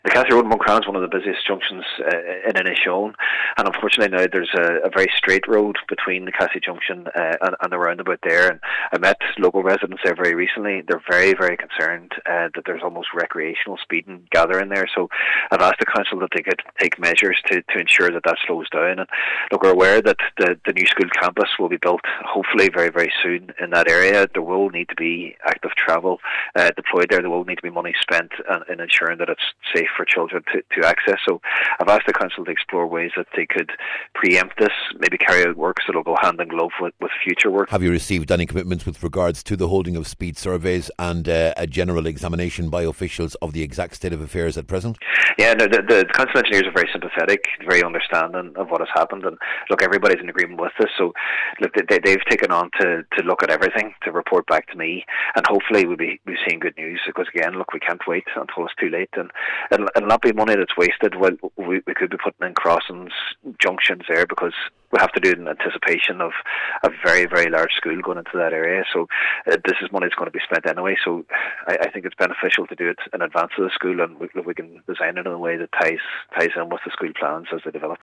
He told officials at a recent municipal district meeting that works to curb speed now would help pave the way for that to happen…………..